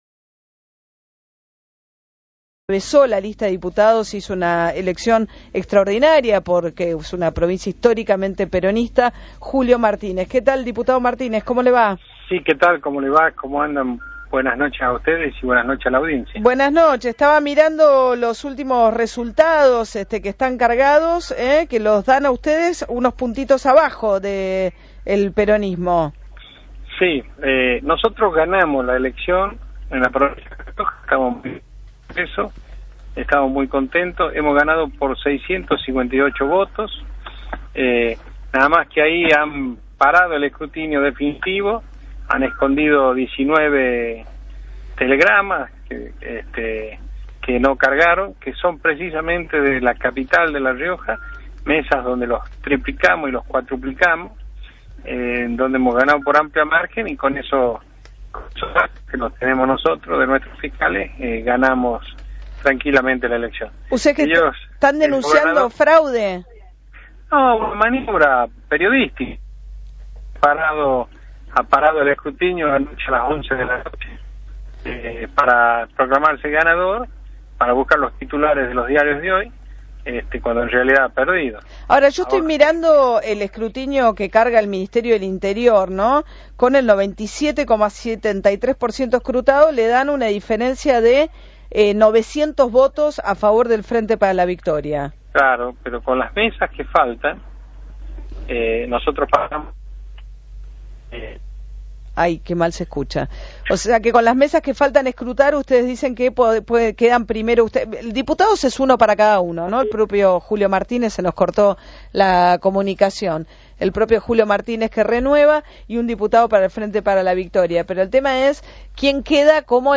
La entrevista de Julio Martínez en Radio Continental